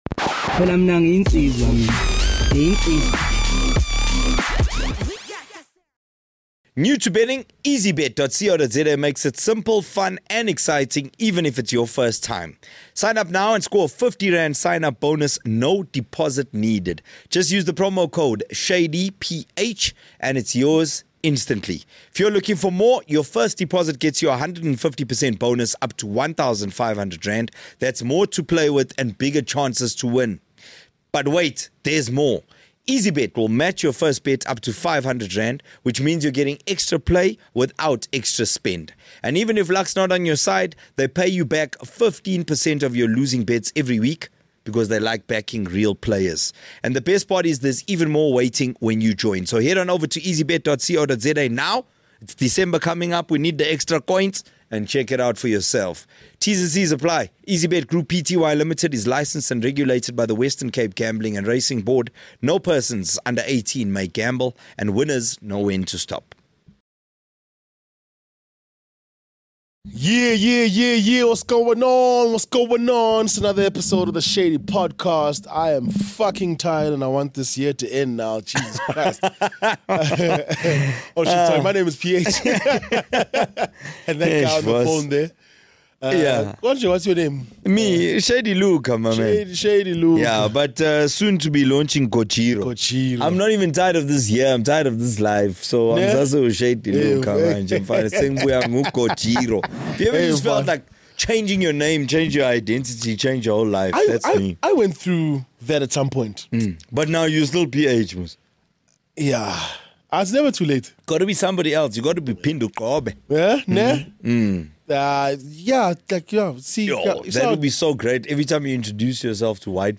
No sugar-coating, no BS, just raw, uncensored conversations.